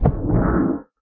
minecraft / sounds / mob / guardian / elder_hit1.ogg
elder_hit1.ogg